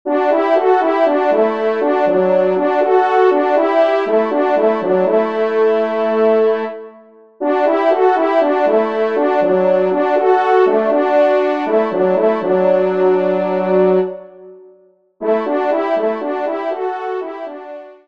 Genre :  Divertissement pour Trompes ou Cors
2ème Trompe